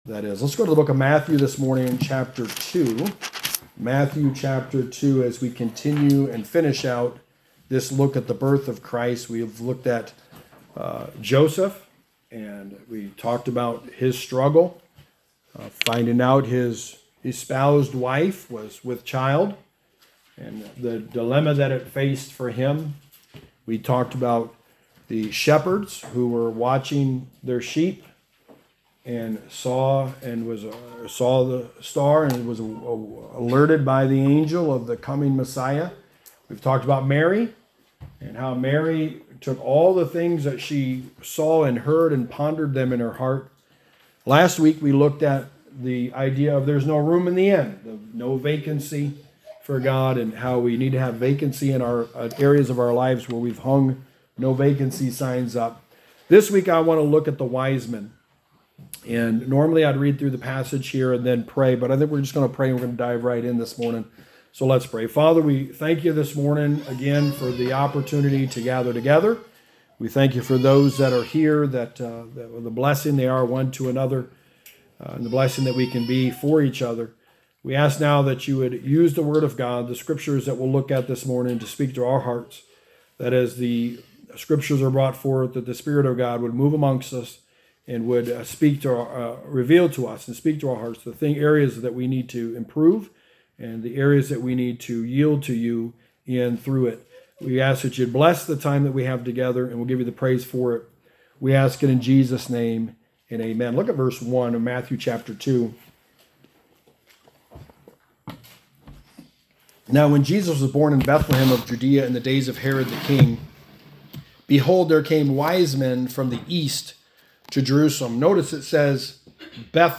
Sermon 5: The Birth of Christ – Wise Men
Passage: Matthew 2:1-8 Service Type: Sunday Morning